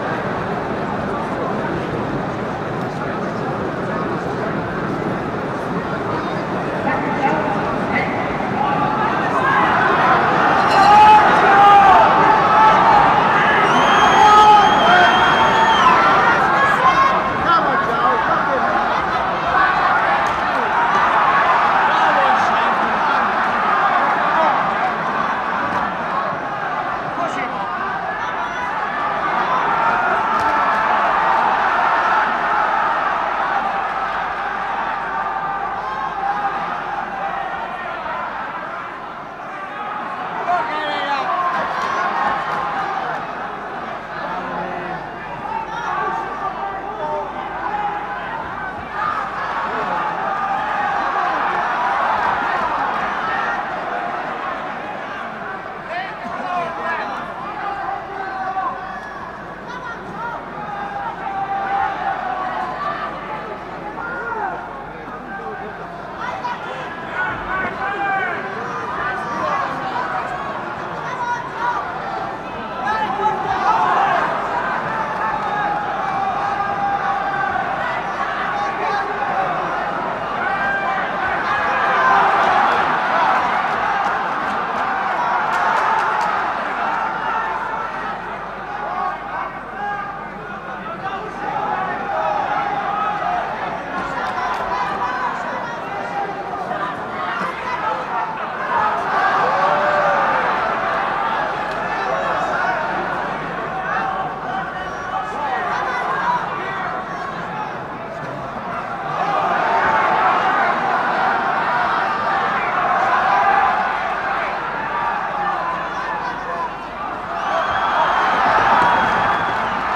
fight-yells-1.ogg